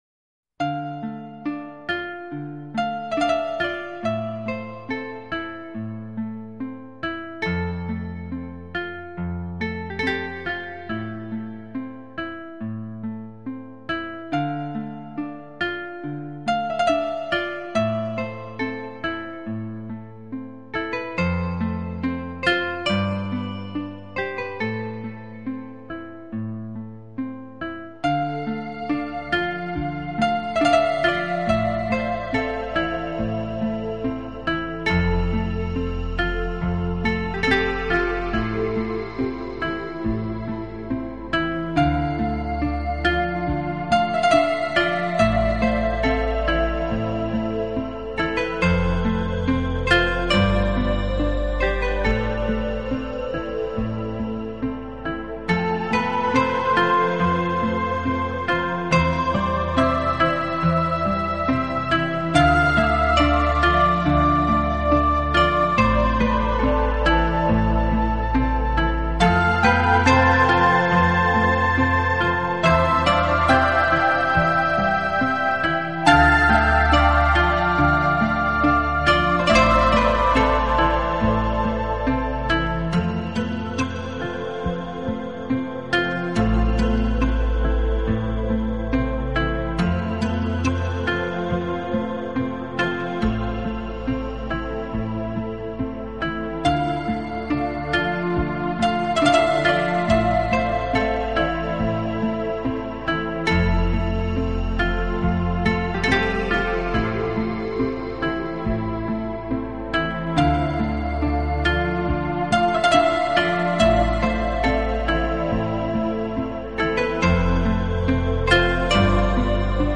【纯音乐】
它不只是新世纪音乐，更是取自
听过他们音乐的读者大致可以了解，该团的音乐风格通常是以电子